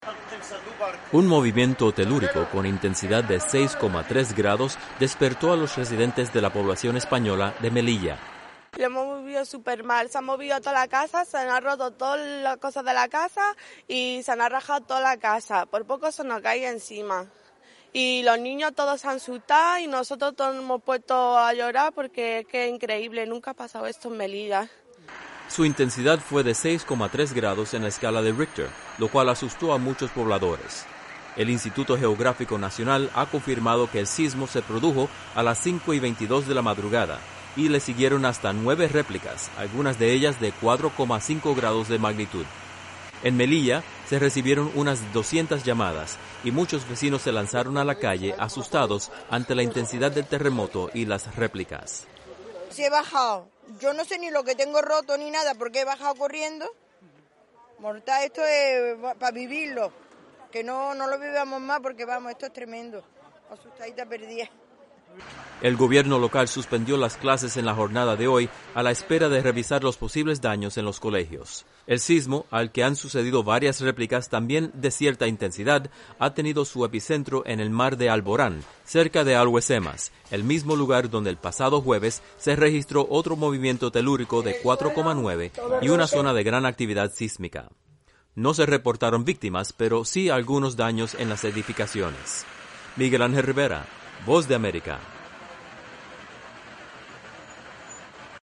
Un terremoto de magnitud 6,3 grados en la escala de Richter sacudió la población española de Melilla y se sintió además en diversos puntos de Andalucía. Los detalles en el siguiente informe.